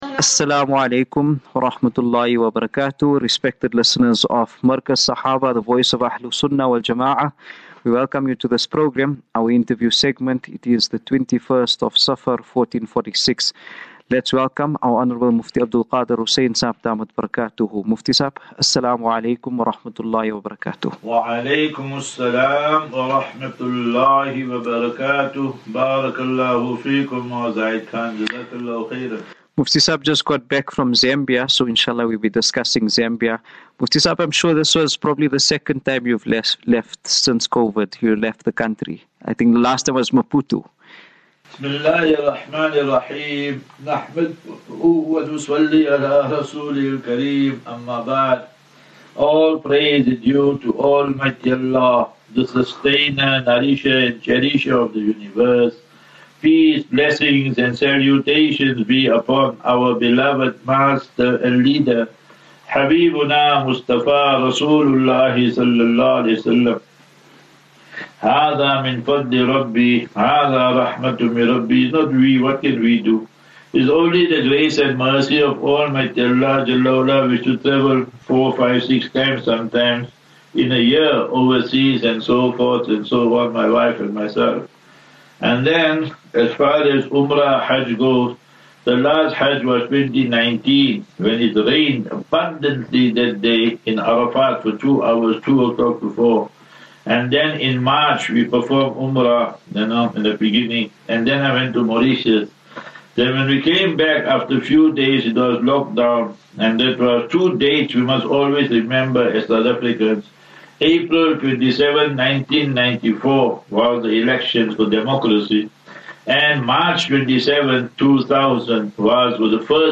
Discussion on Zambia